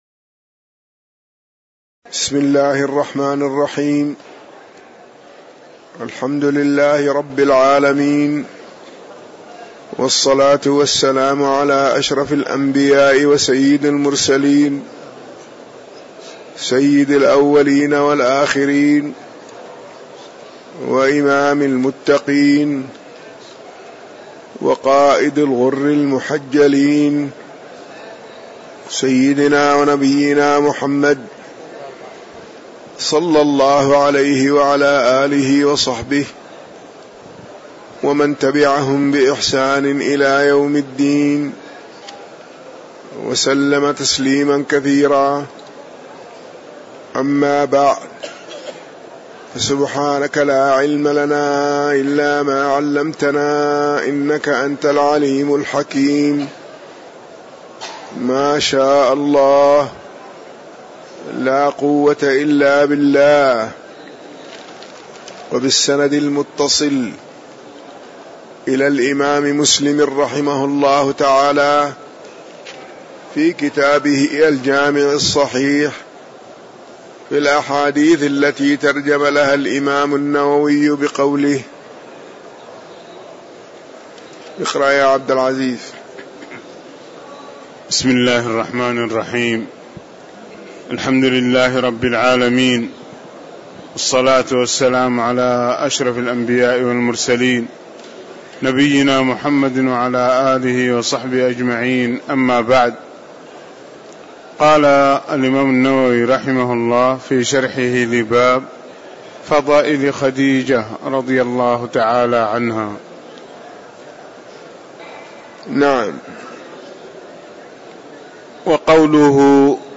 المكان: المسجد النبوي